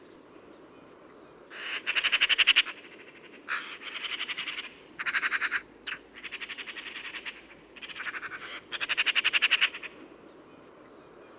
Žagata - KURZEMES PLĀNOŠANAS REĢIONS